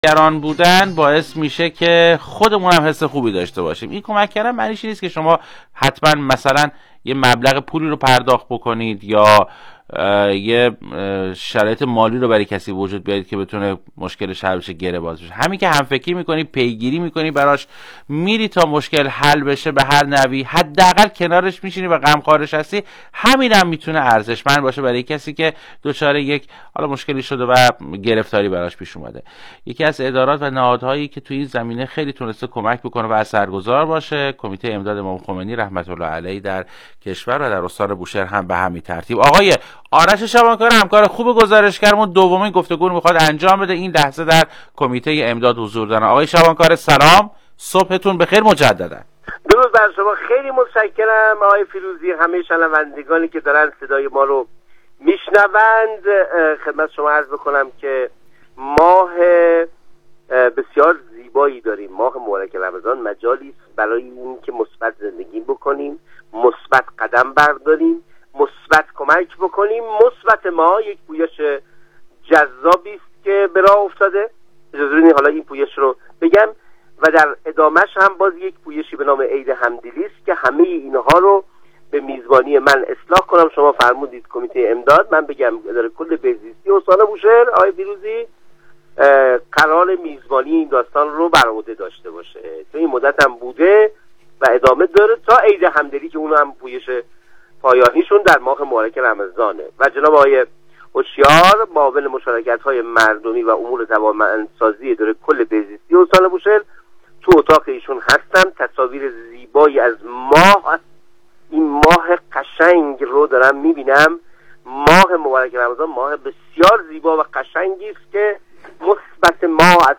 بشنویم| گفتگوی ویژه رادیوبوشهر با معاون مشارکتهای مردمی و امور توانمند سازی بهزیستی استان در خصوص عید همدلی در آستانه عید فطر
علیرضا هوشیار معاون مشارکتهای مردمی و امور توانمند سازی بهزیستی استان بوشهر در گفتگوی ویژه با برنامه زنده و پرشنونده رادیو سلام صدا و سیمای مرکز بوشهر به اقدامات صورت گرفته این اداره کل در خصوص پویش مثبت ماه در ماه مبارک رمضان و همچنین در خصوص عید همدلی و در آستانه عید سعید فطر پرداخت.